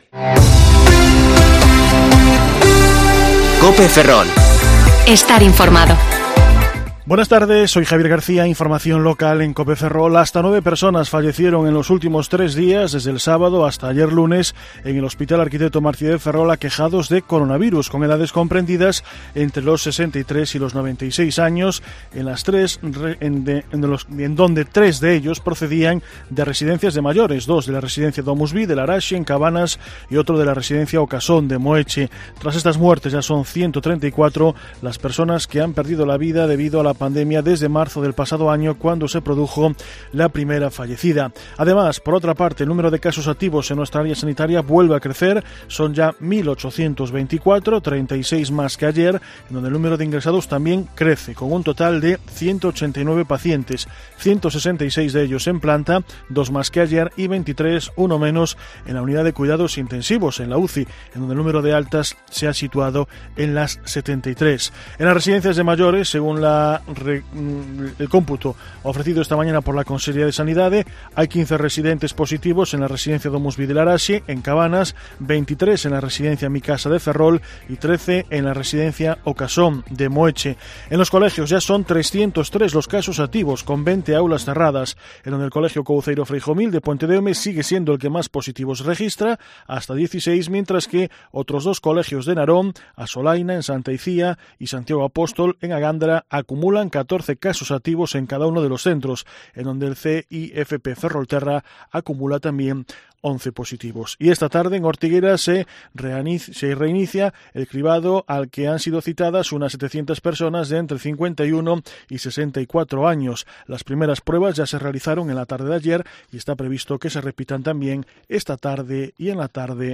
Informativo Mediodía COPE Ferrol 2/2/2021 (De 14,20 a 14,30 horas)